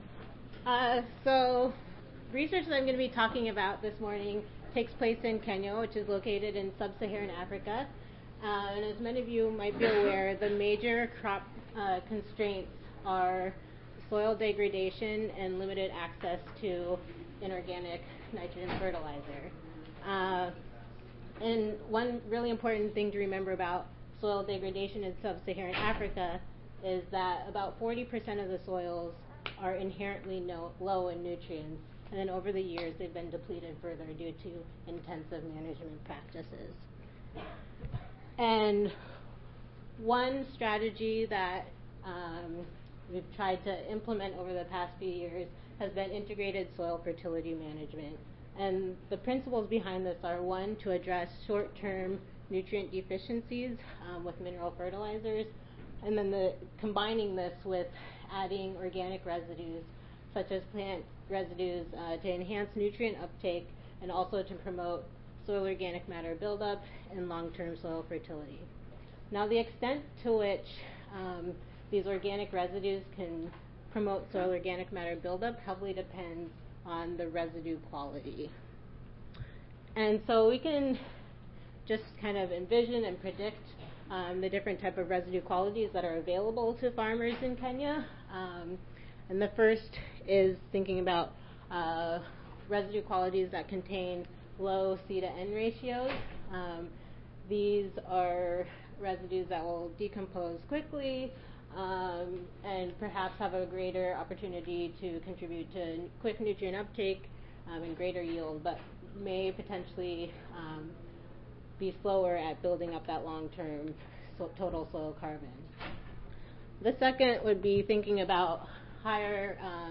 IITA Audio File Recorded Presentation